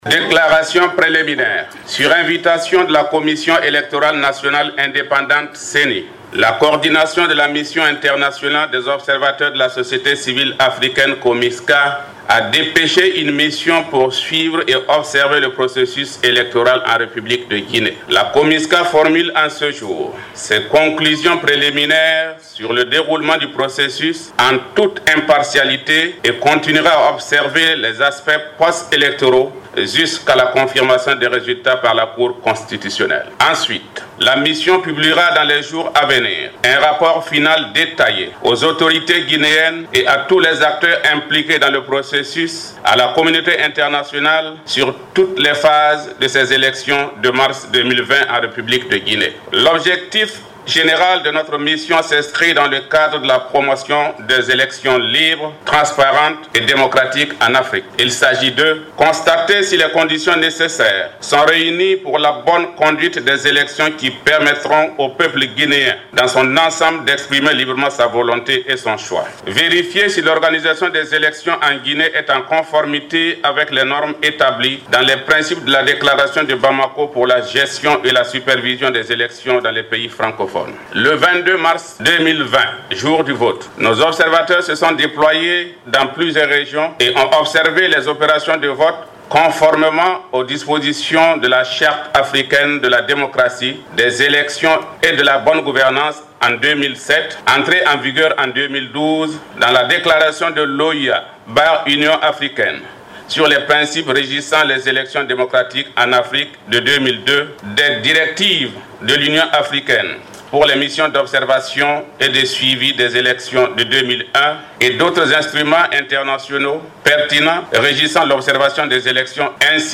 DECLARATION-COMISCA.mp3